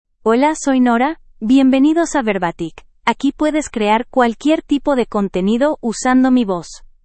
FemaleSpanish (United States)
NoraFemale Spanish AI voice
Nora is a female AI voice for Spanish (United States).
Voice sample
Listen to Nora's female Spanish voice.
Female
Nora delivers clear pronunciation with authentic United States Spanish intonation, making your content sound professionally produced.